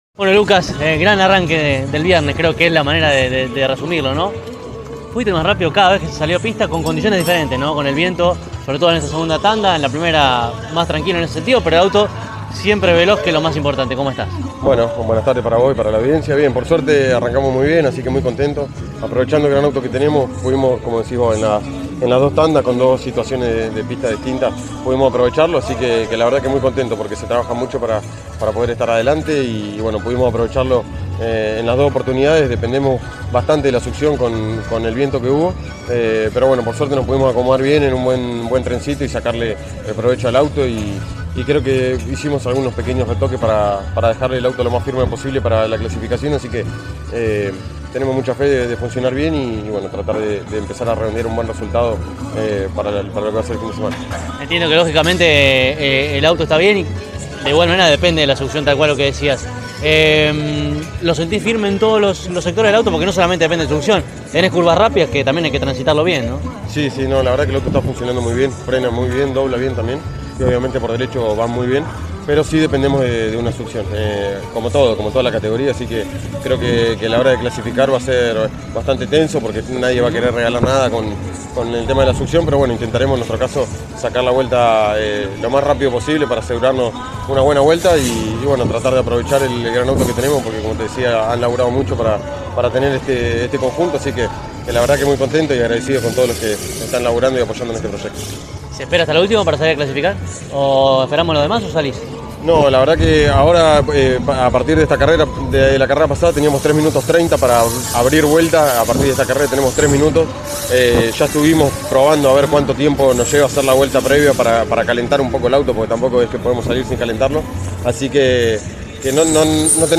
El testimonio del mejor